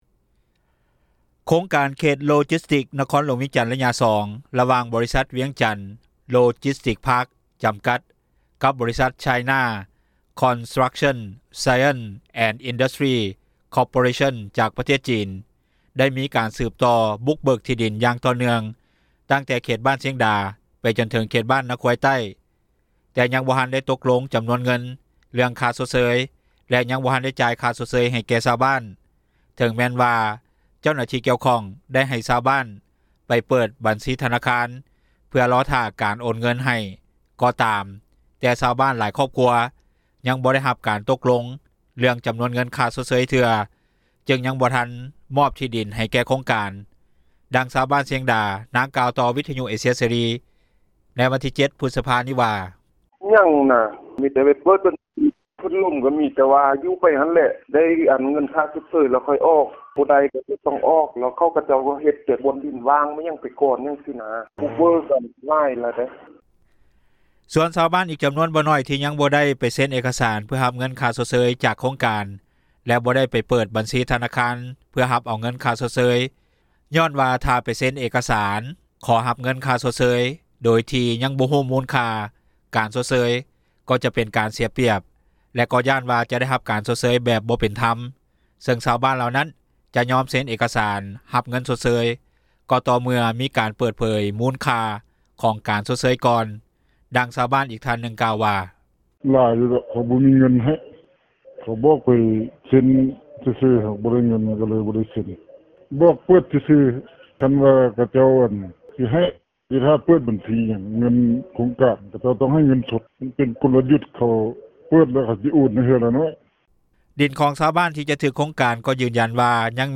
ດັ່ງຊາວບ້ານຊຽງດາ ນາງກ່າວຕໍ່ວິທຍຸເອເຊັຽເສຣີ ໃນວັນທີ 07 ພຶດສະພາ ນີ້ວ່າ: